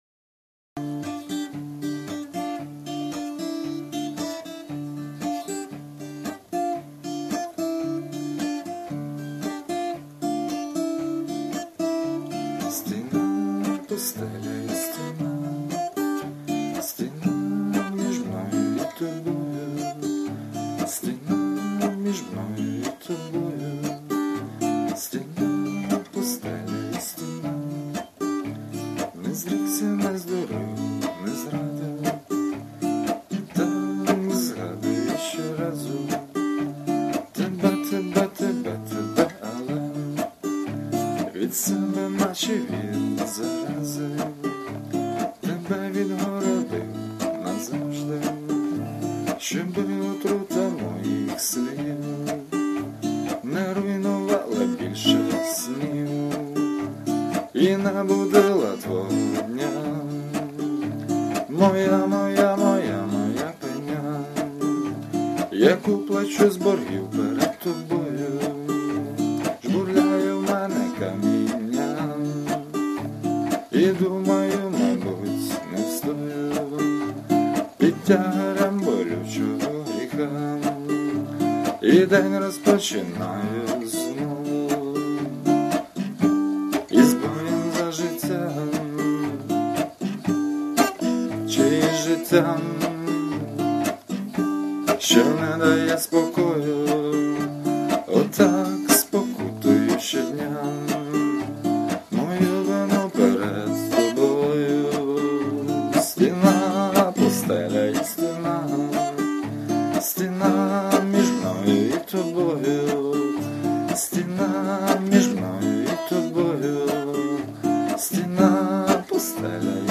Рубрика: Поезія, Авторська пісня